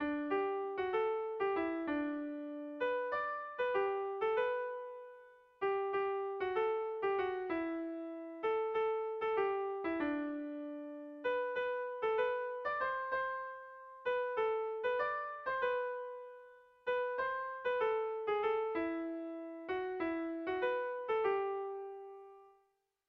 Irrizkoa
Zortziko txikia (hg) / Lau puntuko txikia (ip)
ABDE